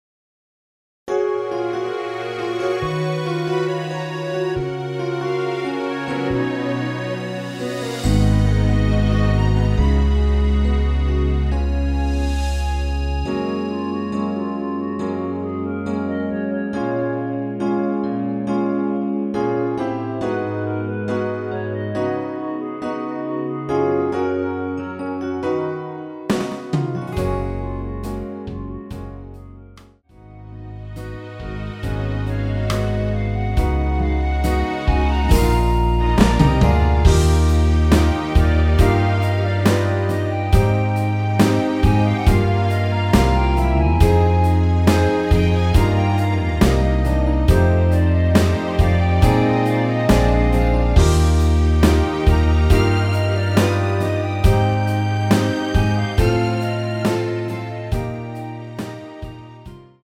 대부분의 여성분이 부르실수 있는키로 제작 하였습니다.
원키에서(+2)올린 멜로디 포함된 MR입니다.
F#
앞부분30초, 뒷부분30초씩 편집해서 올려 드리고 있습니다.